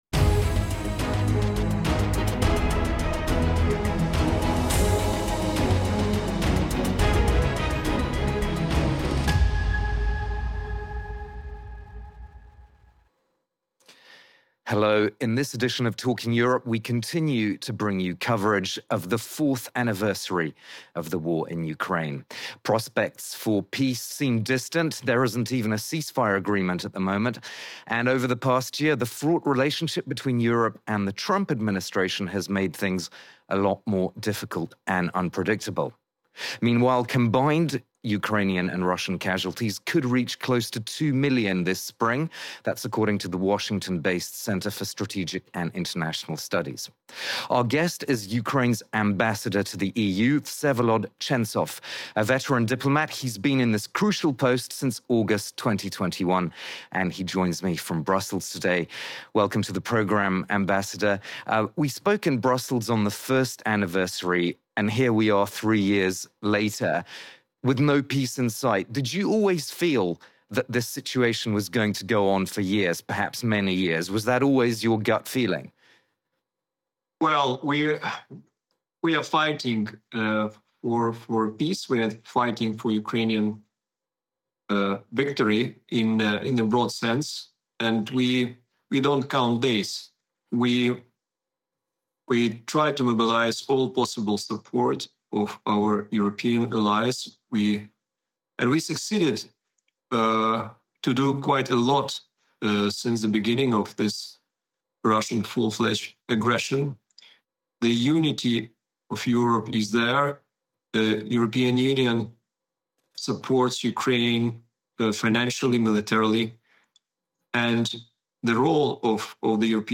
As part of FRANCE 24’s and Talking Europe’s coverage of the fourth anniversary of the war in Ukraine, we host veteran diplomat Vsevolod Chentsov, Ukraine’s ambassador to the European Union. He highlights the critical assistance the EU is providing for the country’s military, budgetary, and energy needs, saying that the solidarity shown by Ukraine’s European allies "cannot be underestimated".